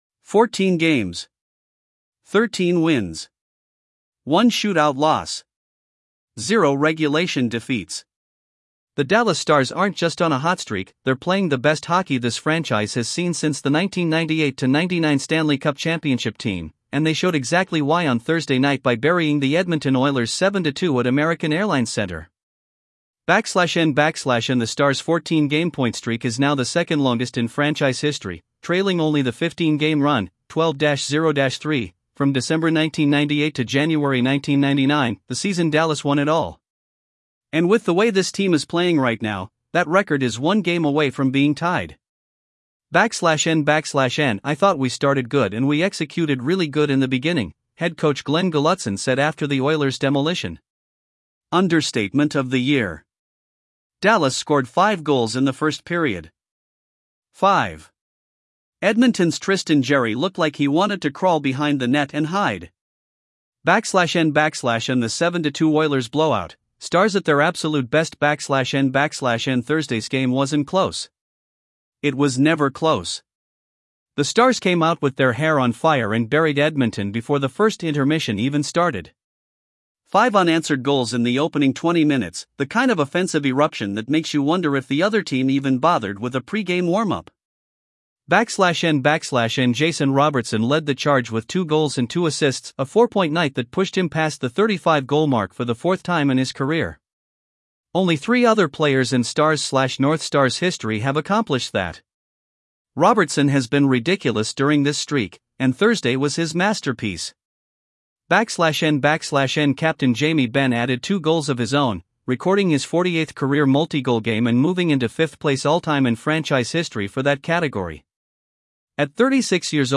AI Voice